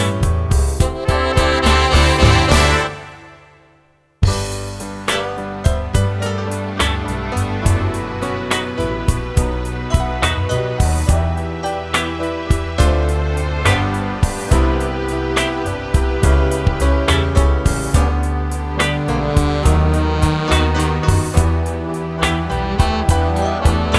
No Female Lead Part Jazz / Swing 4:18 Buy £1.50